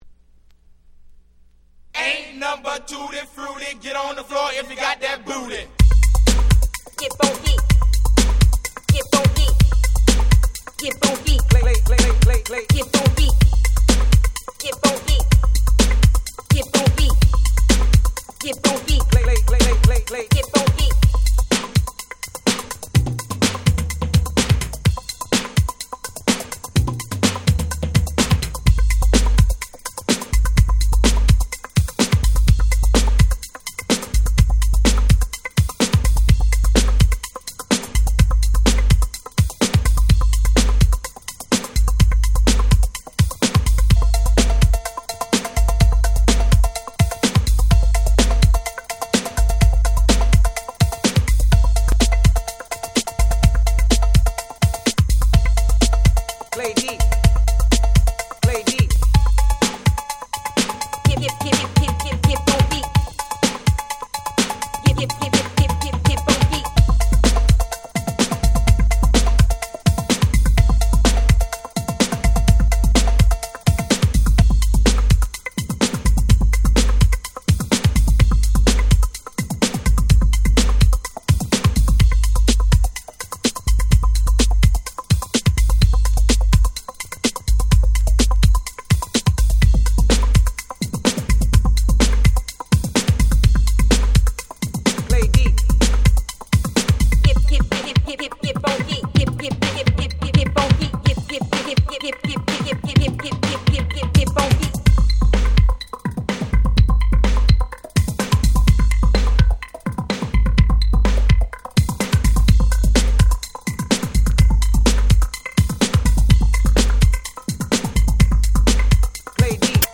88' Old School / Miami Bass / Electro Super Classics !!
説明不要のパーティーヒップホップクラシックスです！！
クールロック マイアミベース オールドスクール エレクトロ